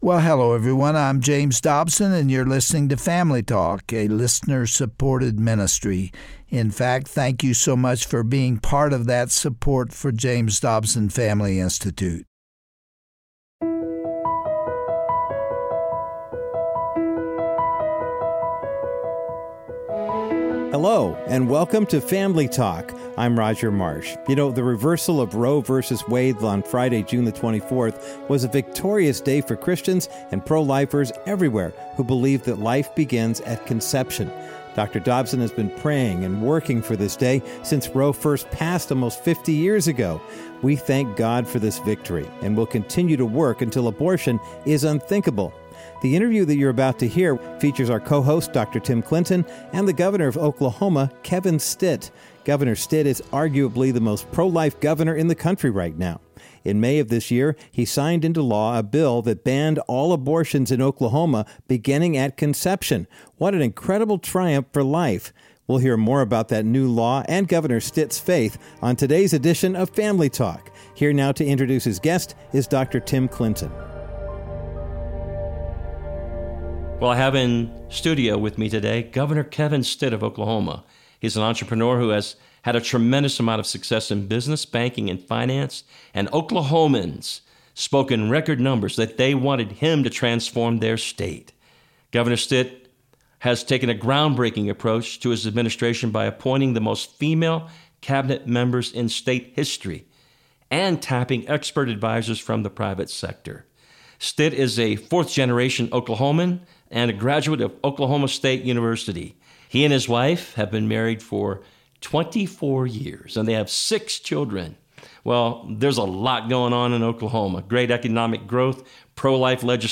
A Conversation with a Pro-Life Governor